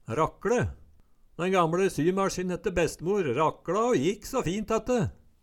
Høyr på uttala Ordklasse: Verb Kategori: Karakteristikk Attende til søk